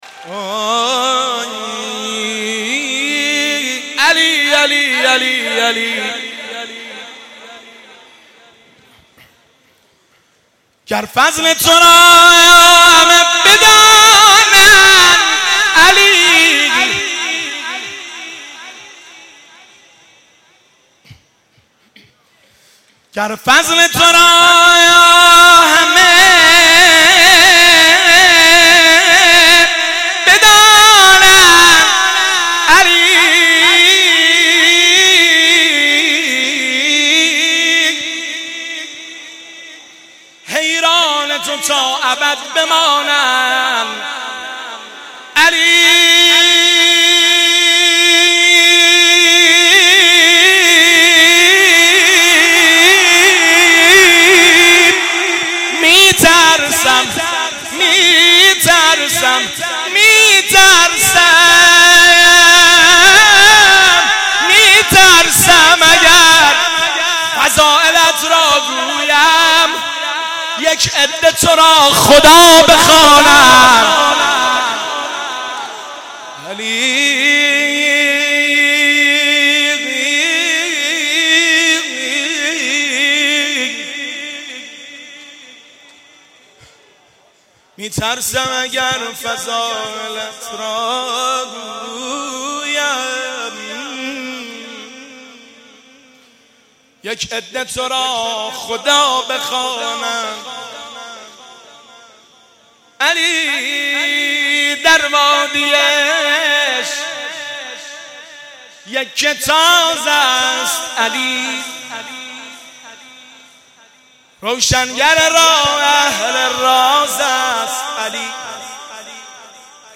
مناسبت : شب بیست و یکم رمضان - شب قدر دوم
قالب : شعر خوانی